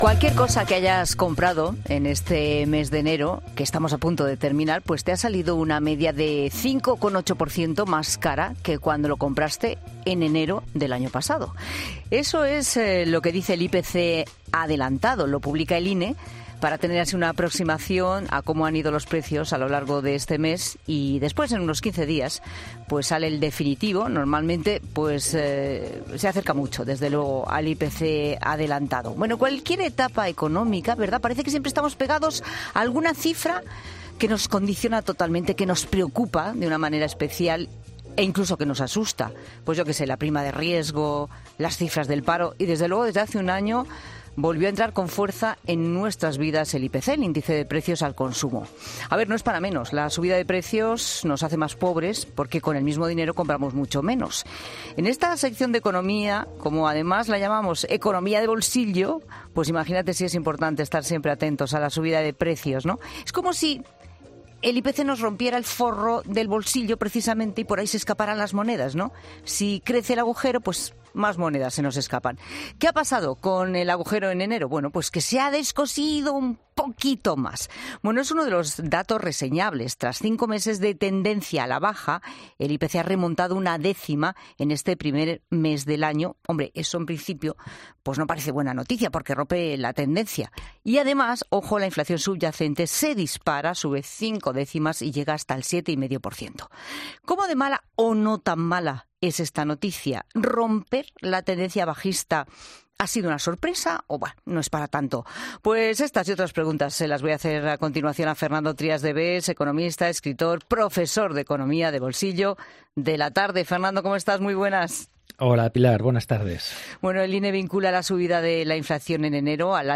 El economista Fernando Trías de Bes analiza en La Tarde el cambio de tendencia en el índice de precios de enero